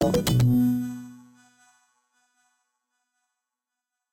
sfx_transition-05.ogg